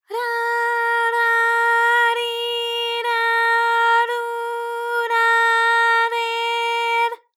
ALYS-DB-001-JPN - First Japanese UTAU vocal library of ALYS.
ra_ra_ri_ra_ru_ra_re_r.wav